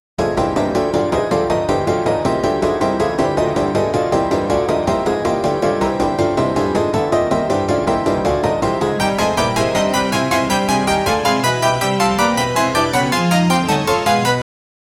hotslot mashine spin reels
hotslot-mashine-spin-reel-z2tedoy4.wav